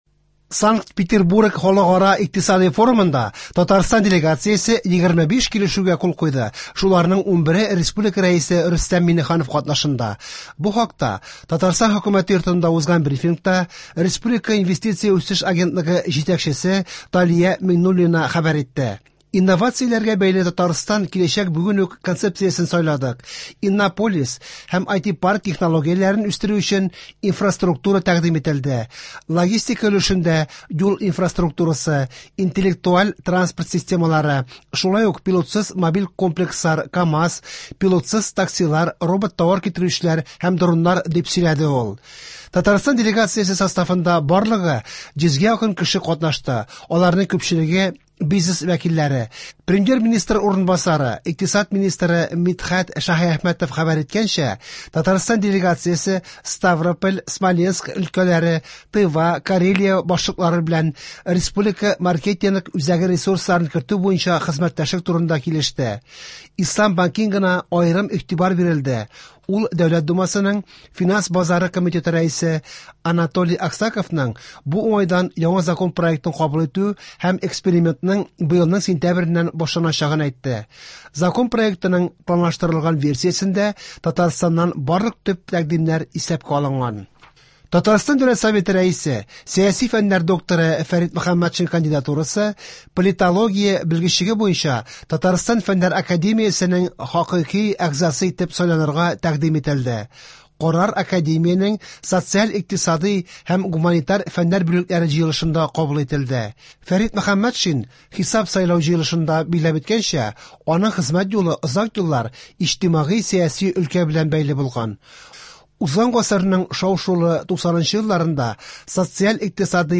Яңалыклар (21.06.23)